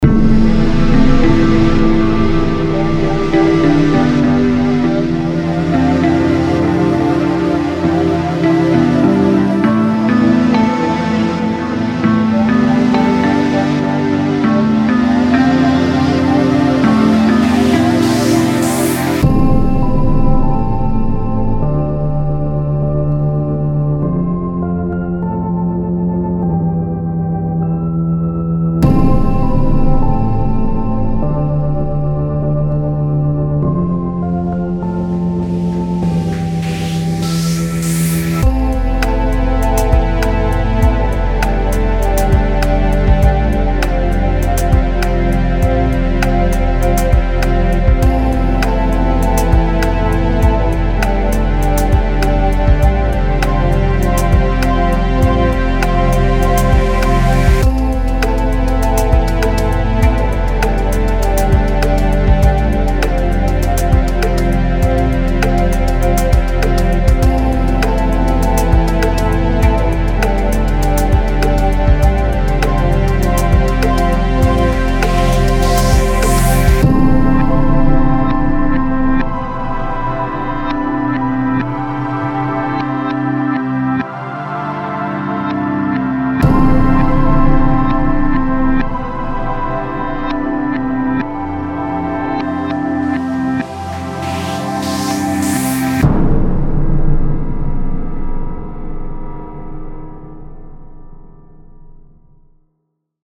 潜入独特的环境美乐!
这是郁郁葱葱的另类电子产品和音景的独特集合。
期望拥有美丽的郁郁葱葱的垫子，空灵的音景，foley，深沉的低音，低沉的鼓声，脉动的合成器，有节
3完全混合和掌握